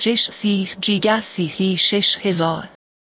persian-tts-female1-vits